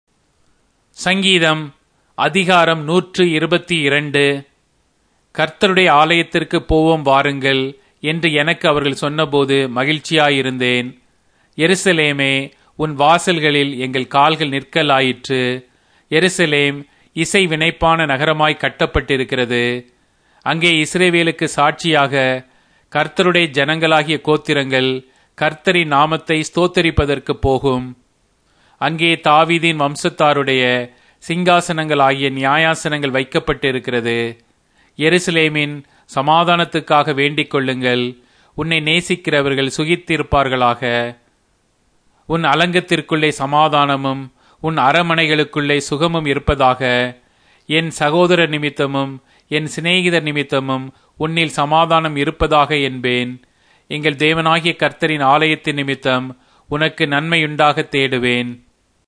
Tamil Audio Bible - Psalms 141 in Esv bible version